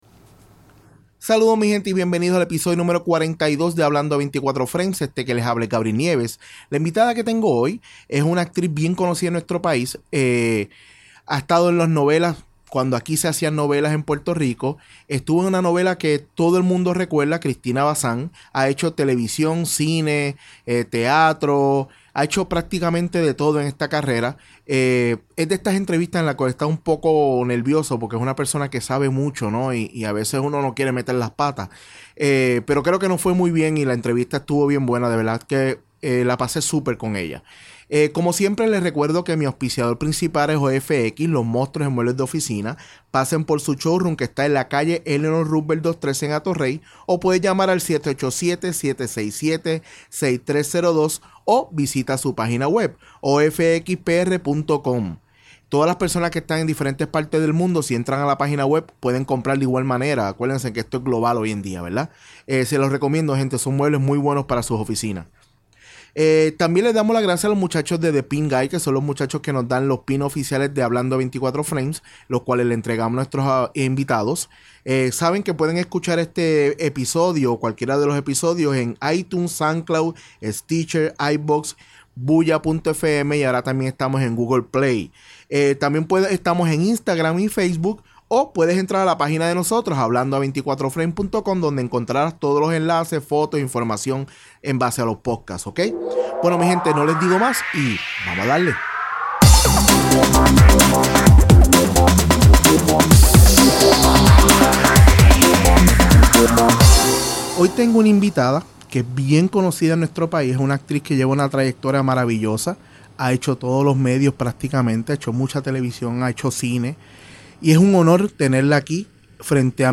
En el episodio #42 converso con una de las actrices más conocidas de nuestro país. Hablamos de su carrera, de lo activa que esta en las redes sociales, de NY y de sus películas.